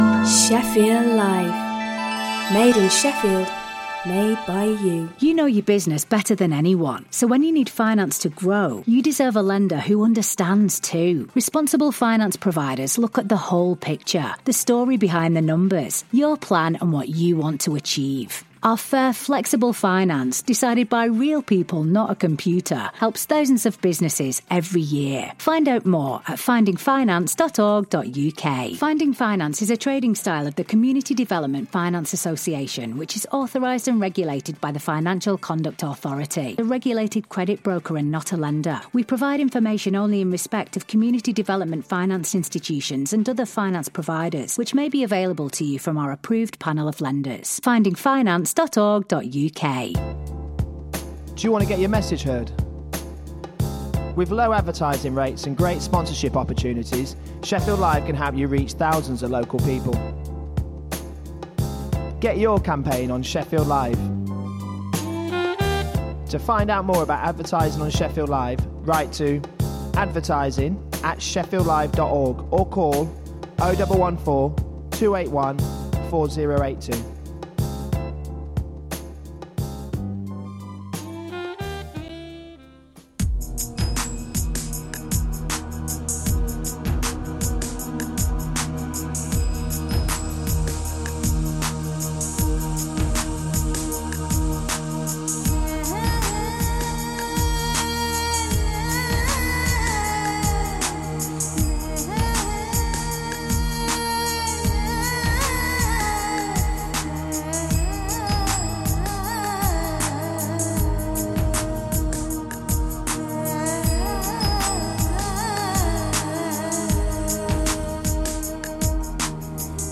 Shefffield Live presents…Aaj Ka Sabrang : A mix of different flavours of Asian music from the sub-continent and chat.